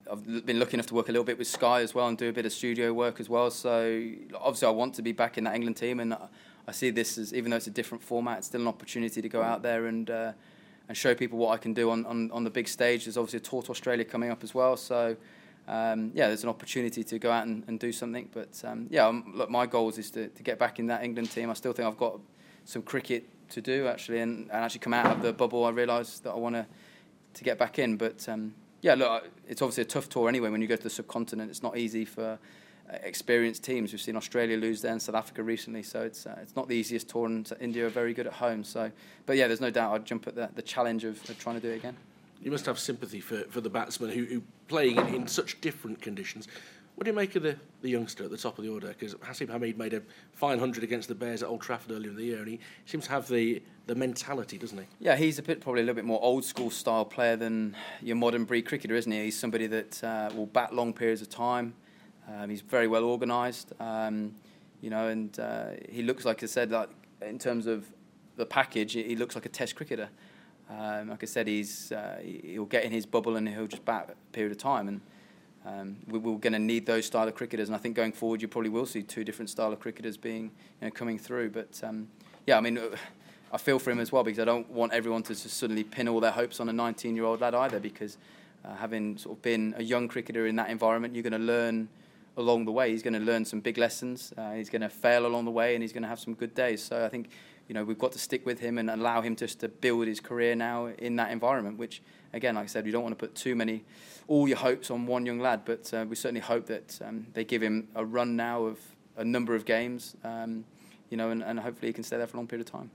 Warwickshire captain Ian Bell tells BBC WM he'd give up playing for the Perth Scorchers in Australia this winter to help out England in India.